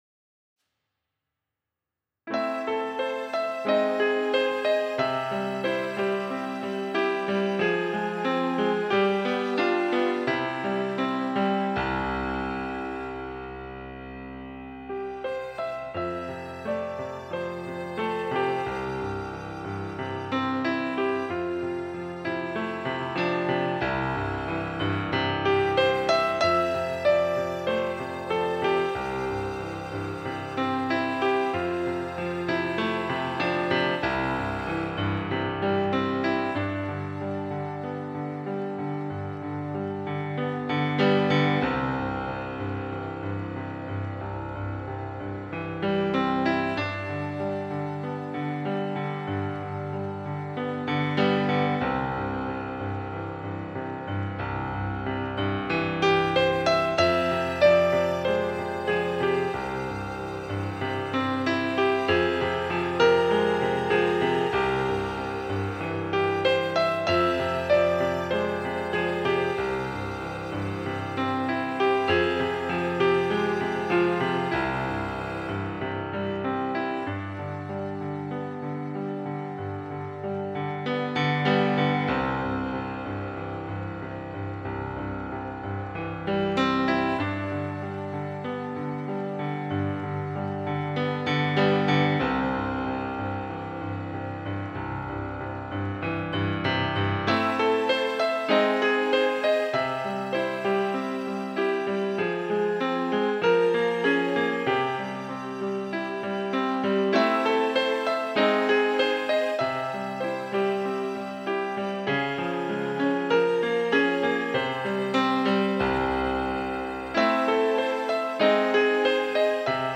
Original Piano / Keyboard